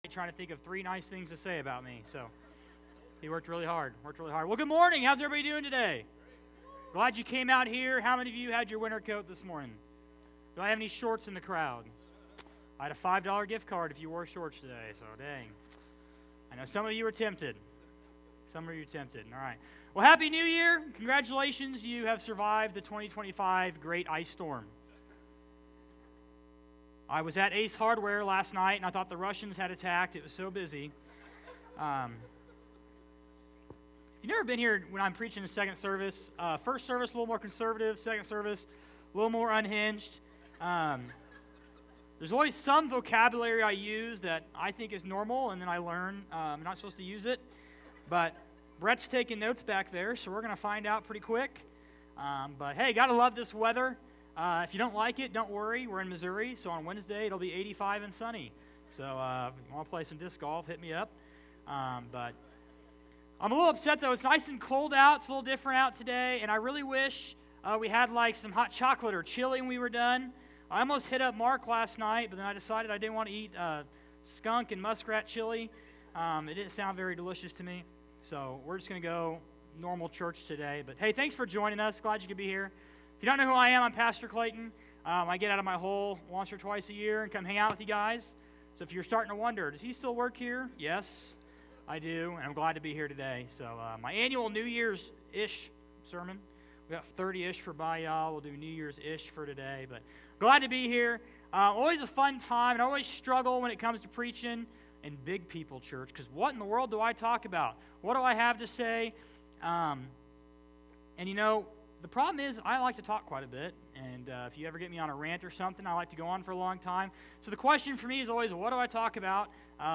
Sermons | Brighton Assembly